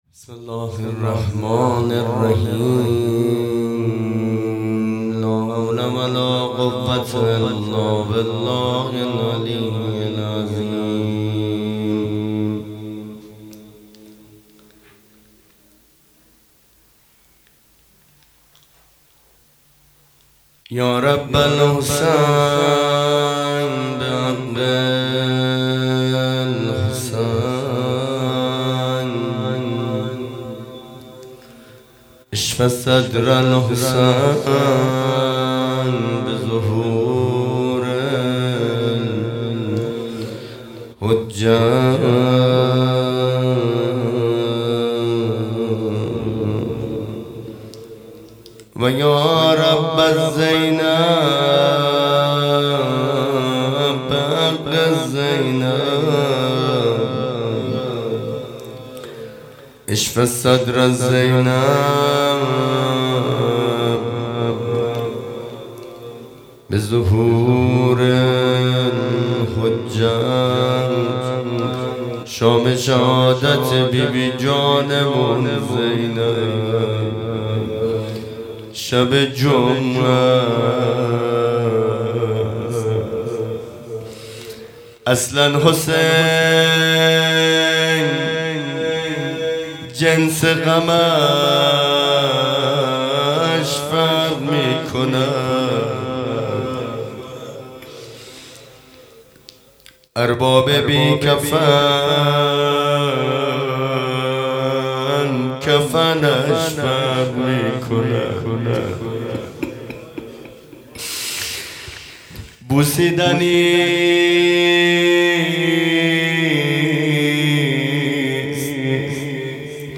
خیمه گاه - هیئت بچه های فاطمه (س) - روضه | اصلا حسین جنس غمش فرق می کند | 28 بهمن 1400